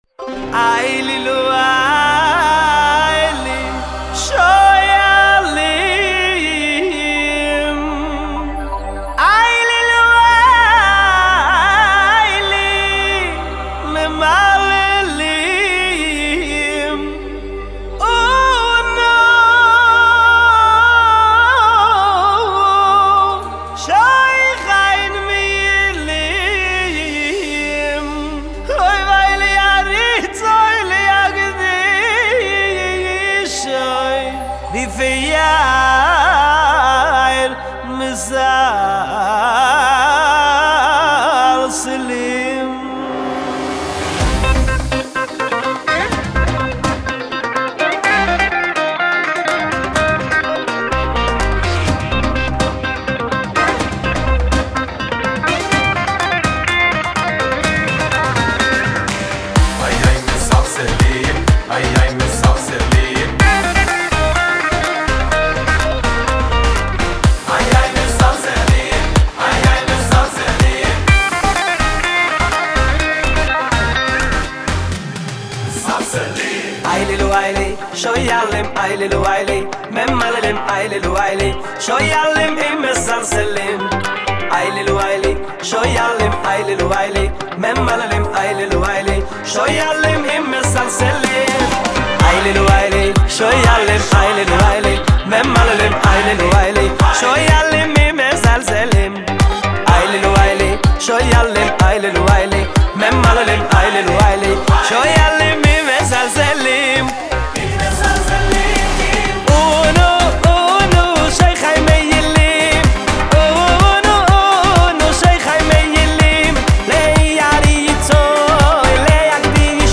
שירים חסידיים